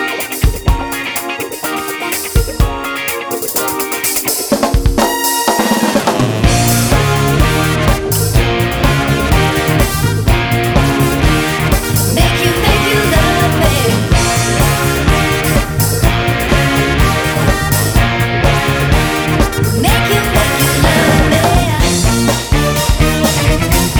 Live Version Pop (1960s) 4:28 Buy £1.50